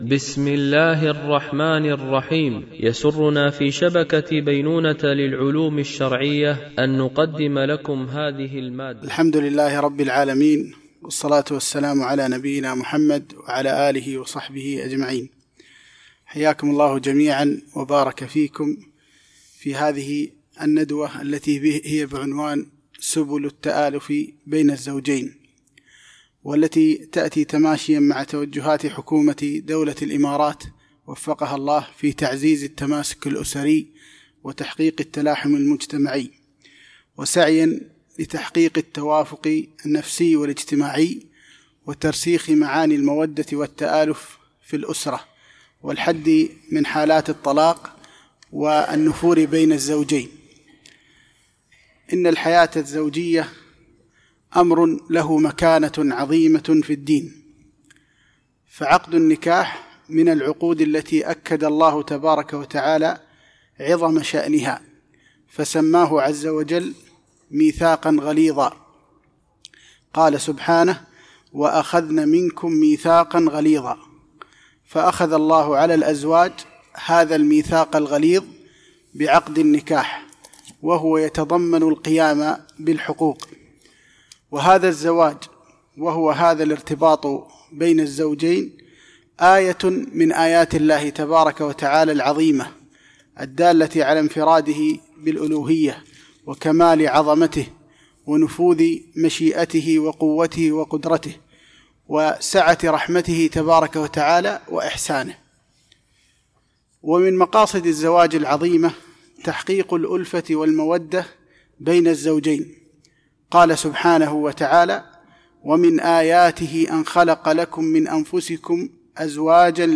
ندوة علمية بعنوان: سبل التآلف بين الزوجين
الشيخ: مجموعة من المشايخ